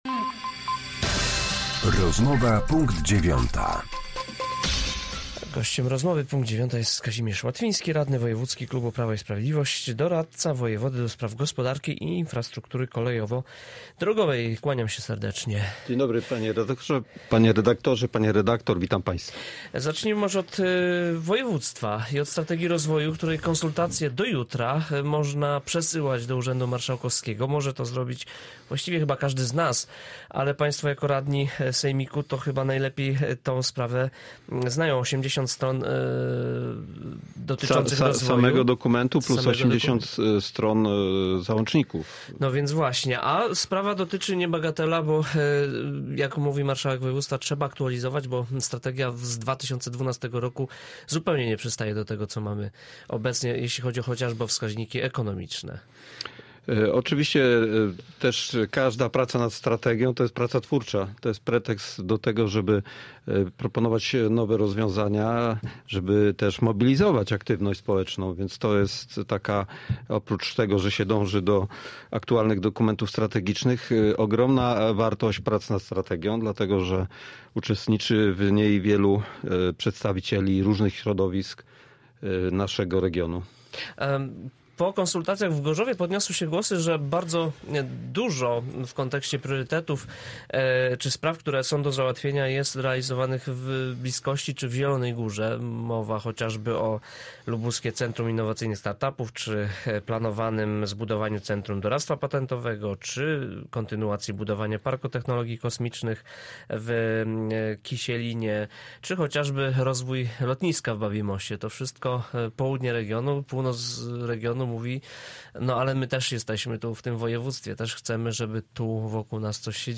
Z radnym wojewódzkim klubu Prawo i Sprawiedliwość, doradcą wojewody ds. gospodarki i infrastruktury kolejowo-drogowej rozmawia